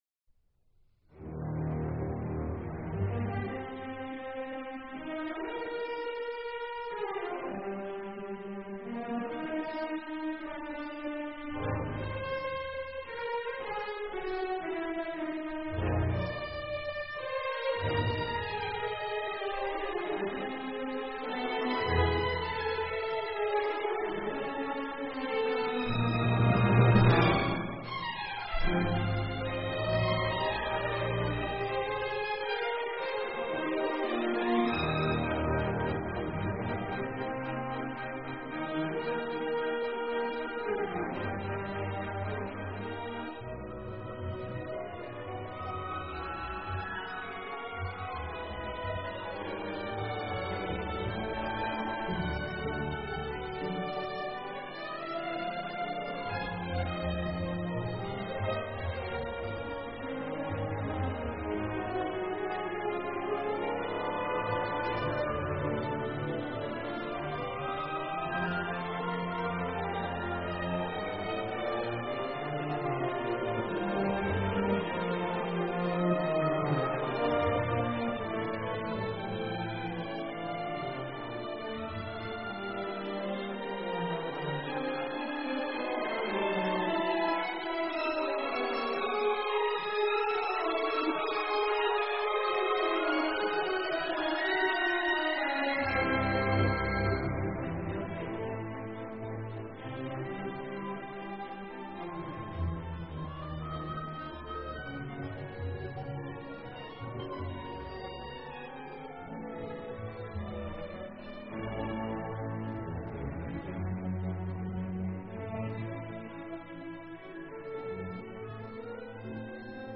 小提琴獨奏
音樂類型：古典音樂